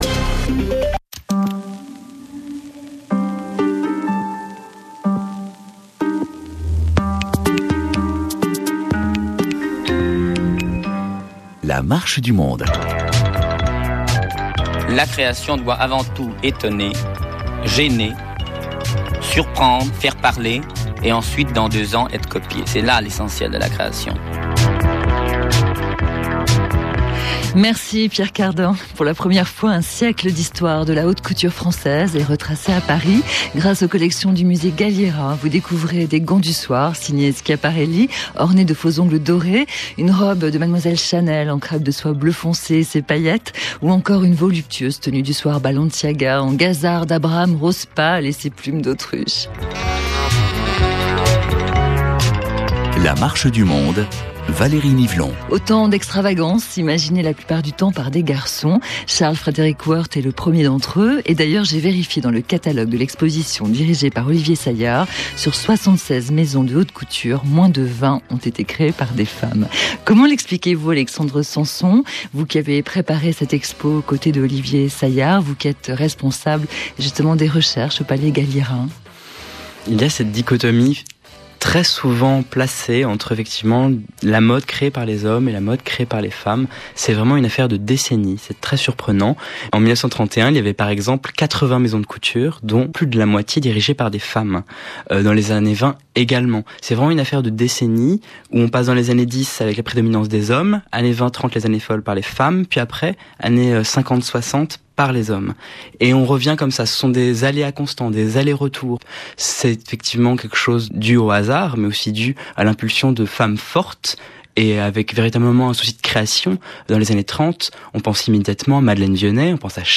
Participez avec vos étudiants à un défilé de mode à l'aide de ce reportage audio.